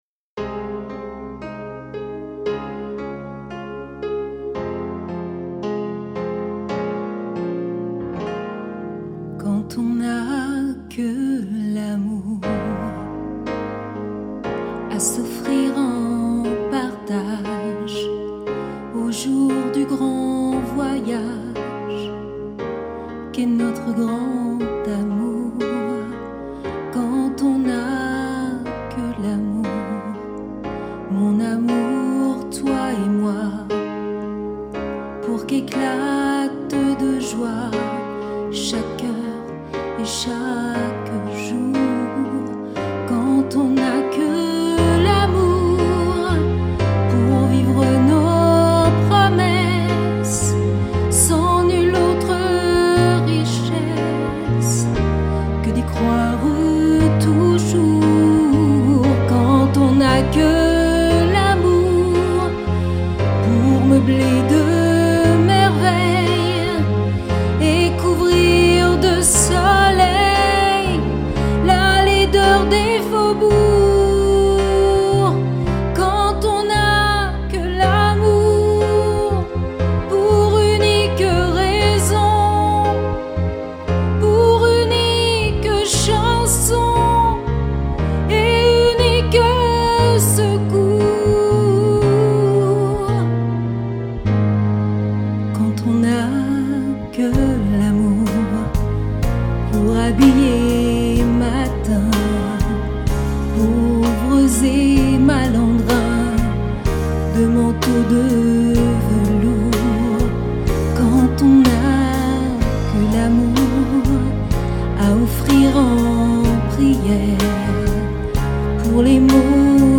35 - 50 ans - Mezzo-soprano